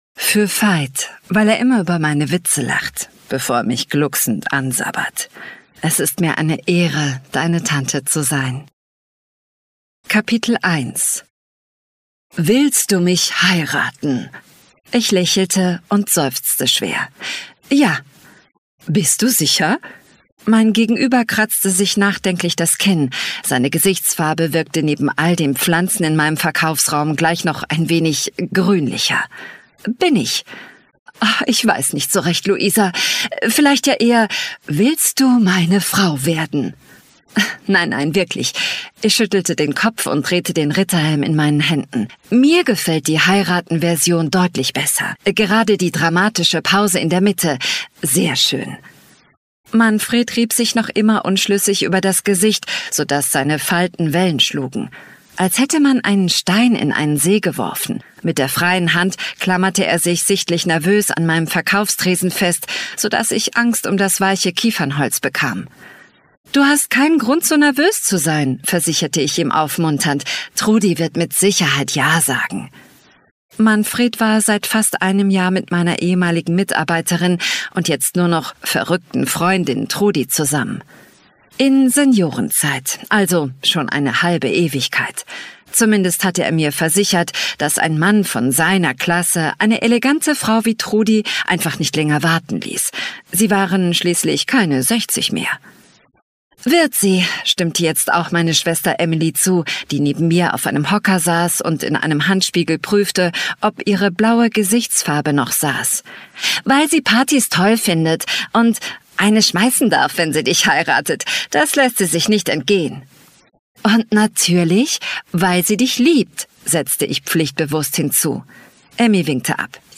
Native voices